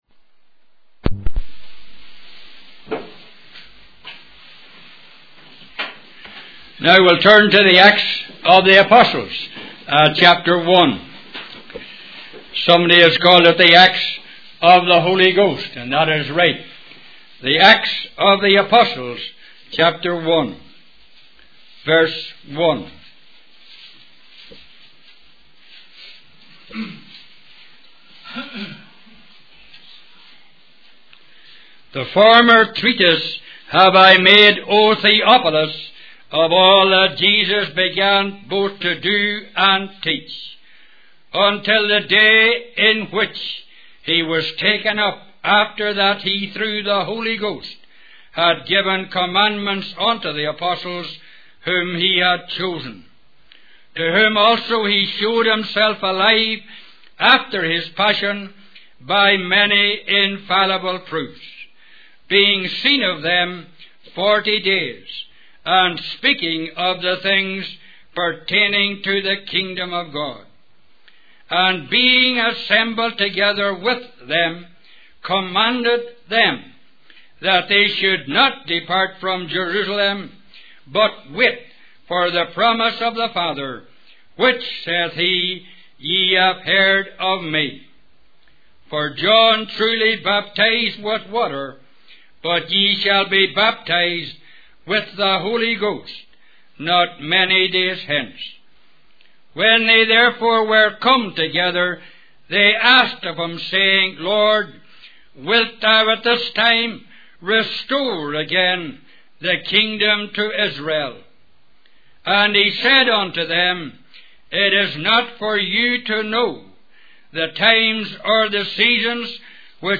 In this sermon, the preacher discusses the prevalence of hatred in society and shares a story about Catherine Booth, the mother of the Salvation Army, who showed love and compassion to a woman in need. The preacher emphasizes the importance of standing up against hate and being a witness for God. He then talks about the power of the Holy Ghost and the need for obedience to receive it.